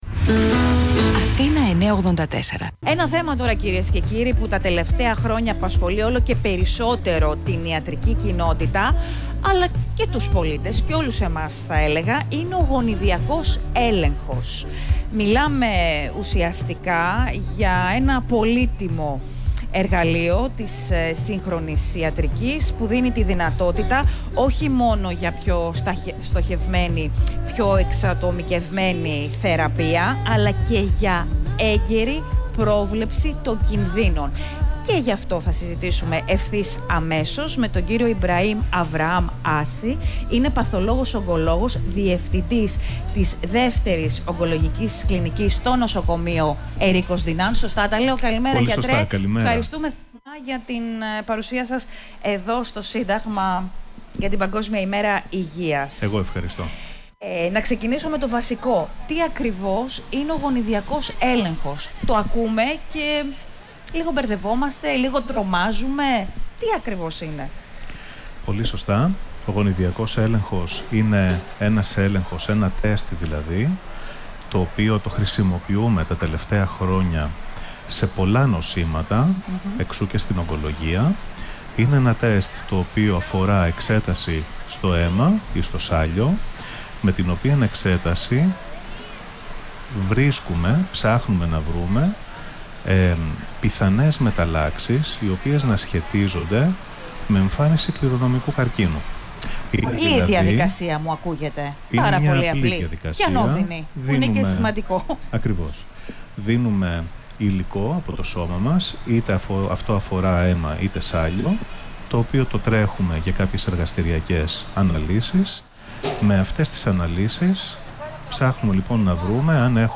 Συνέντευξη στον Αθήνα 9.84 για τον Γονιδιακό Έλεγχο
Mε αφορμή την Παγκόσμια Ημέρα Υγείας, το Ερρίκος Ντυνάν Hospital Center σε συνεργασία με τον ραδιοφωνικό σταθμό Αθήνα 9.84 βρέθηκαν στην καρδιά της πόλης, στο Σύνταγμα, στέλνοντας ένα ισχυρό μήνυμα: η πρόληψη σώζει ζωές.